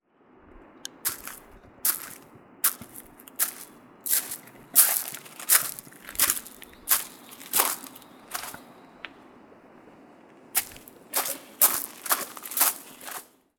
39.砂利道を歩く【無料効果音】
ASMR効果音道/道路
ASMR